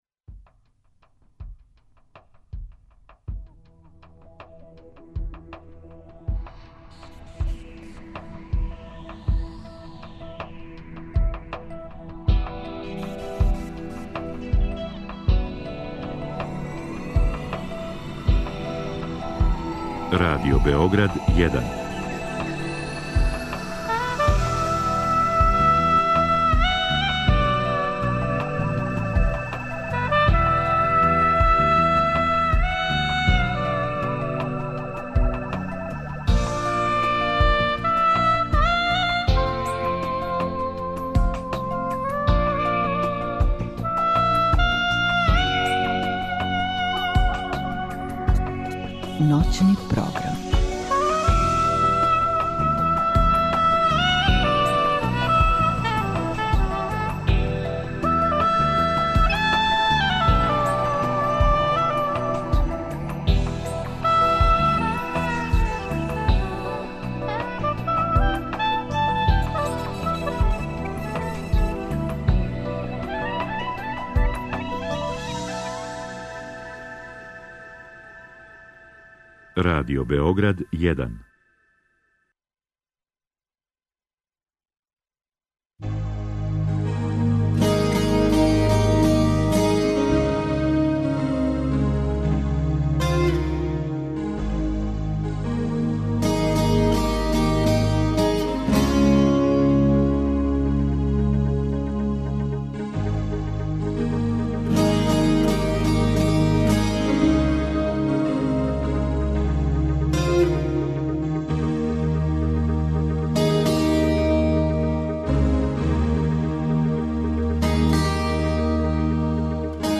Са гостима из ове необичне креативне "фабрике" разговарамо о њиховим представама, радионицама, курсевима и како своје снове претварају у живописну јаву у коју онда и ми можемо да уђемо и постанемо њен део.